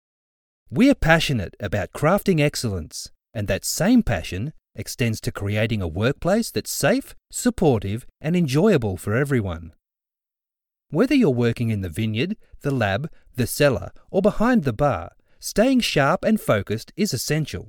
Male
Adult (30-50), Older Sound (50+)
My natural Australian accent is genuine and engaging.
E-Learning
Australian Coporate Training
0110Corporate_training_VO_sample.mp3